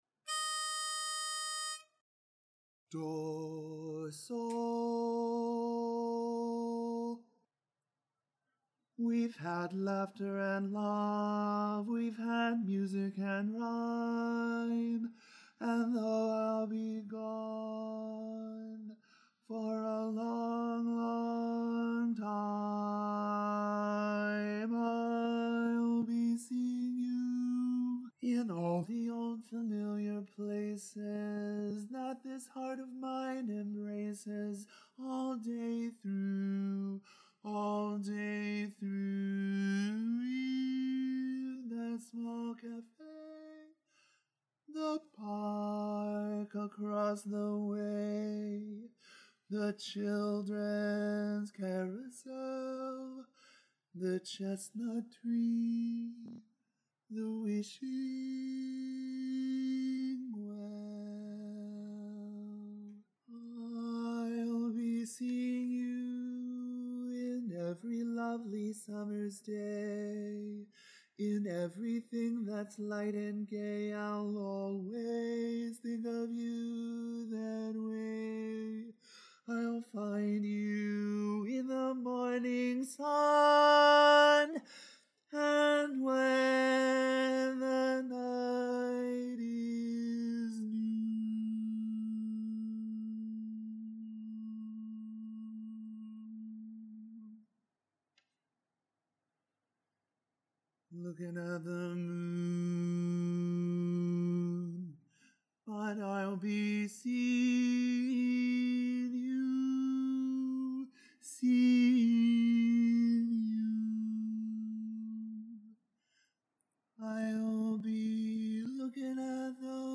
Ballad
Barbershop
E♭ Major
Bari